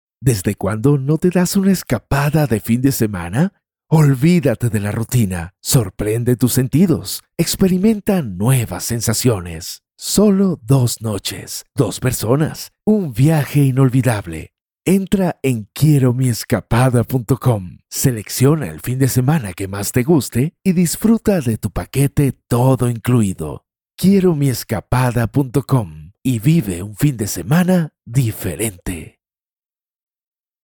Teenager (13-17) | Yng Adult (18-29)